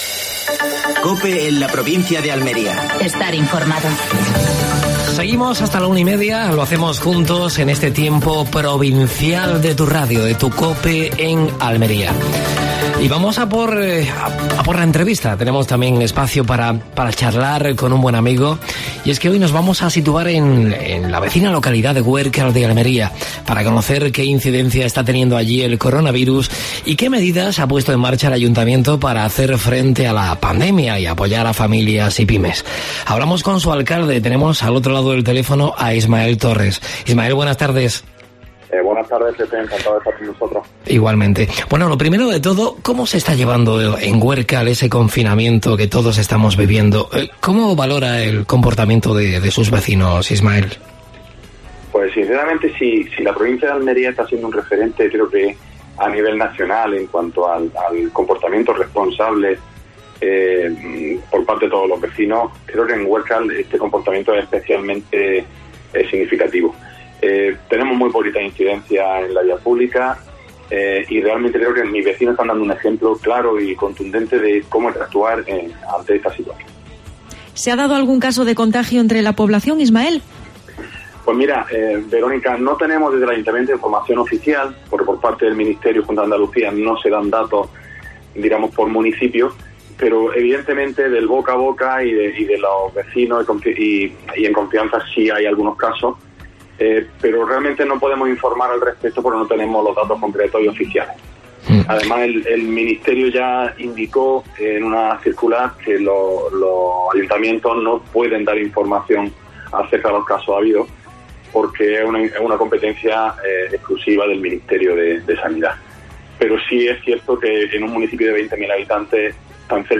Actualidad en Almería. Entrevista a Ismael Torres (alcalde de Huércal de Almería).